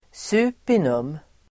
Uttal: [²sup'i:num]